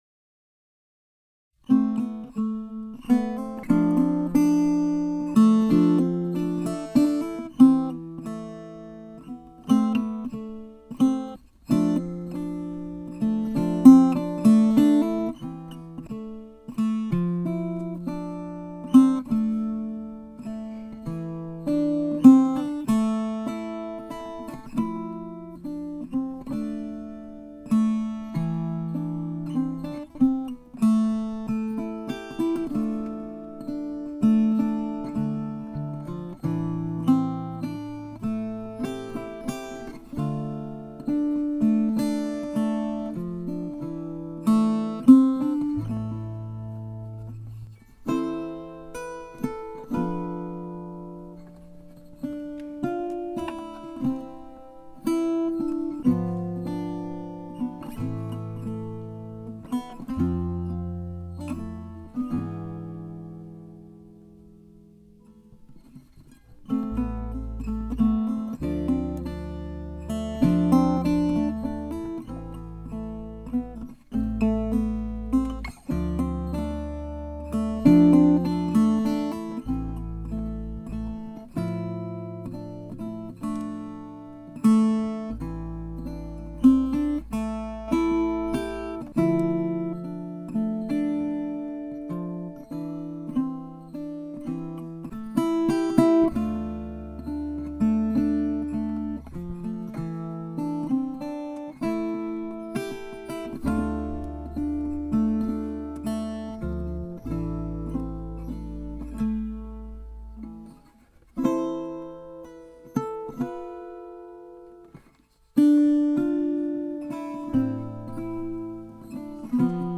I played this tune on a Victor banjola.